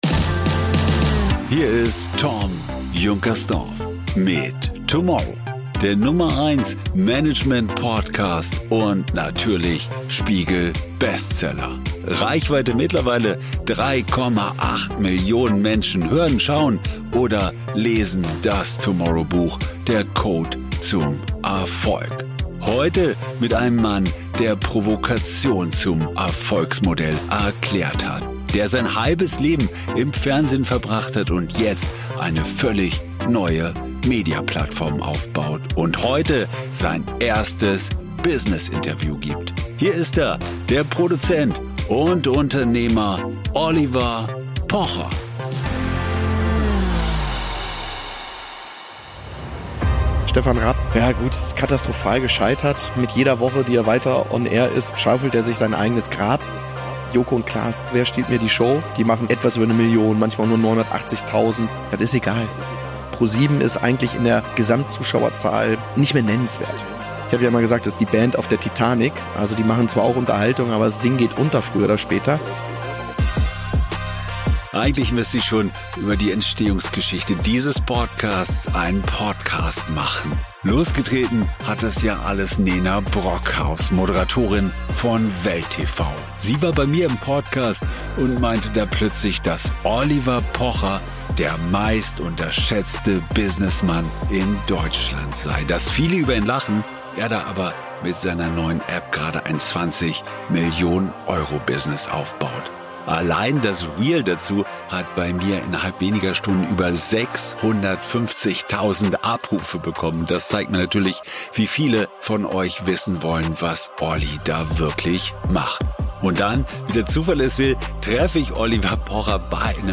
Diese Folge gibt es auch als TOMorrow Videopodcast auf YouTube: Hier siehst du Oliver Pocher beim Interview live im Roomers Hotel in München.